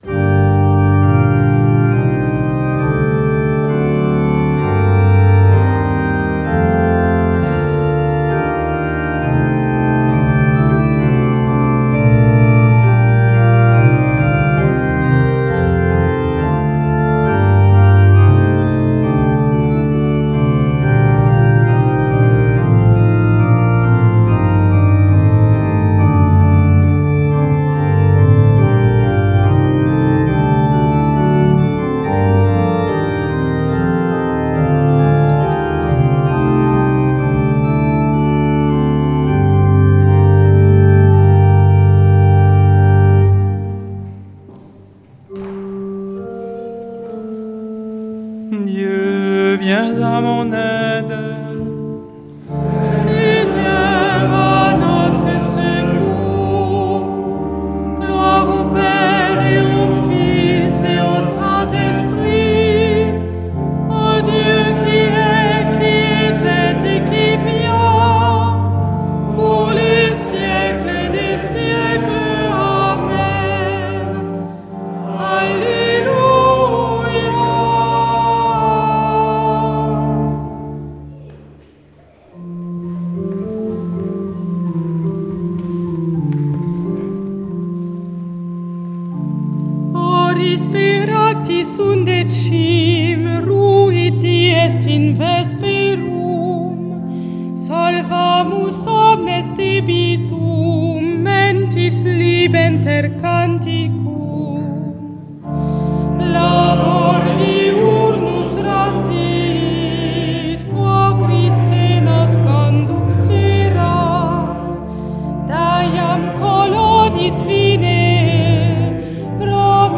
Vêpres
Tout l'été, vivez les vêpres depuis la Crypte de Saint Amadour à Rocamadour